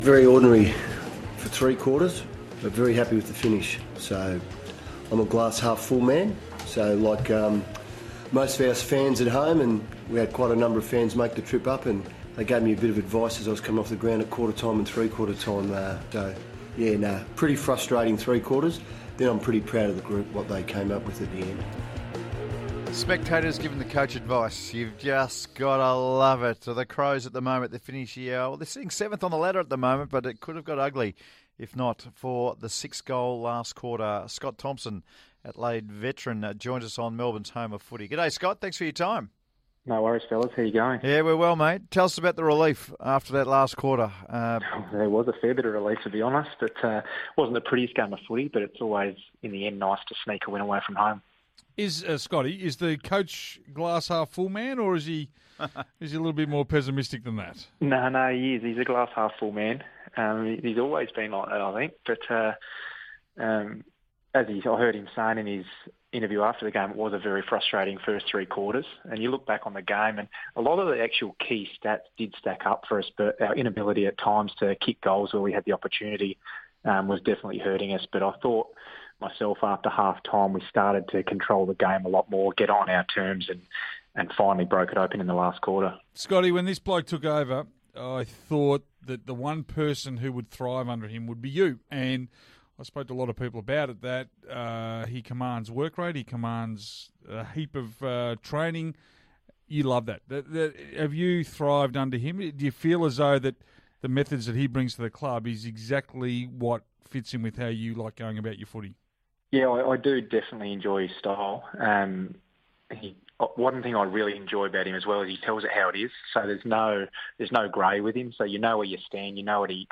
Crows veteran Scott Thompson spoke on Melbourne Radio station SEN after Adelaide's gutsy win over the Lions